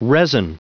Prononciation du mot resin en anglais (fichier audio)
Prononciation du mot : resin